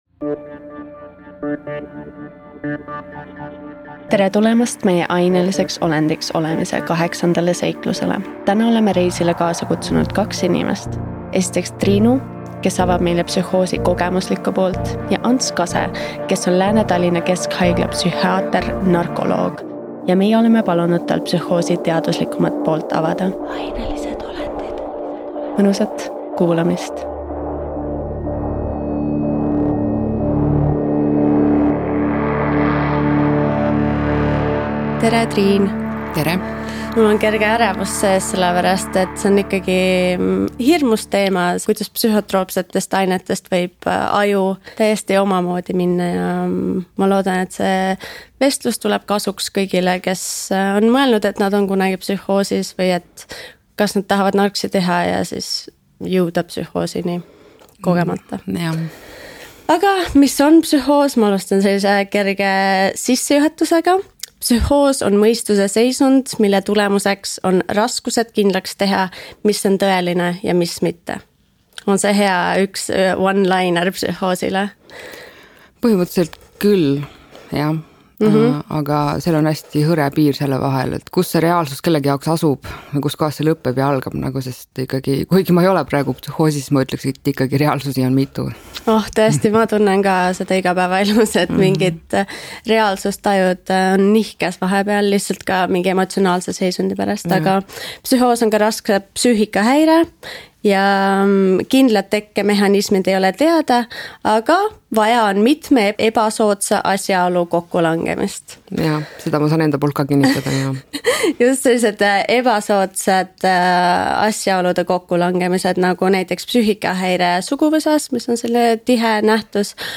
Sarja "Ainelised olendid" eesmärk on muuta psühhotroopsete ainete ümber käivaid vestlusi sisulisemaks – mitte hirmutada, vaid rääkida ainete headest ja halbadest külgedest. Igas saates on koos saatejuhiga üks külaline, kellega tulevad jutuks nii teadmised, müüdid